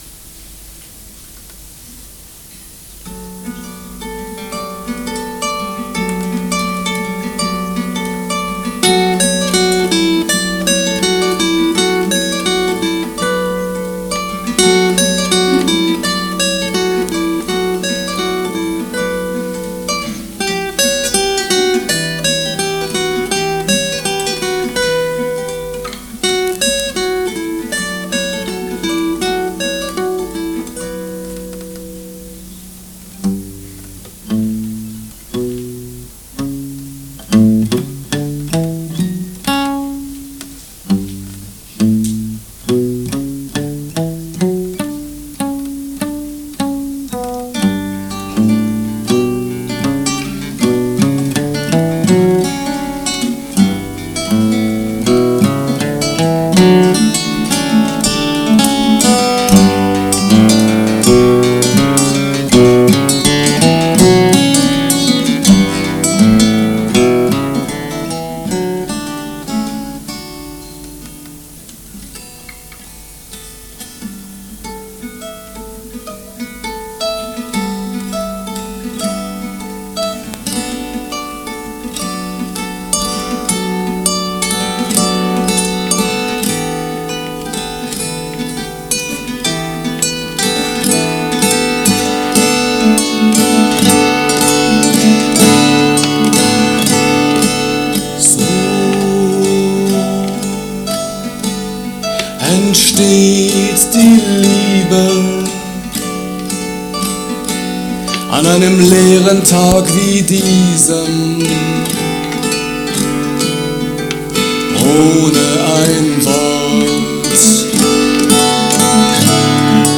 Gitarrenduo (1989 – 93)
Duo für zwei Gitarren und Gesang
live 10.07.93 ibp München)Herunterladen